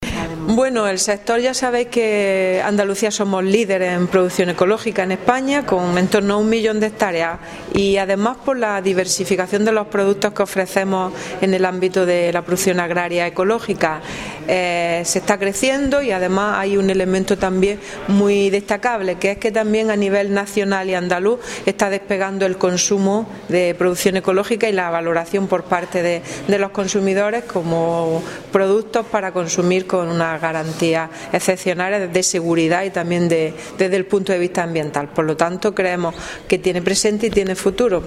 Declaraciones consejera sector ecológico